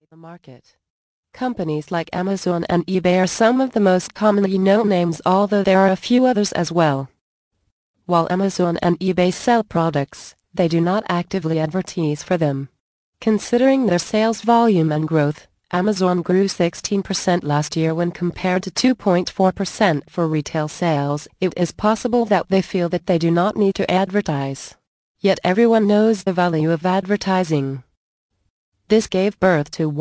Finding The Best Affiliate Products to Promote audio book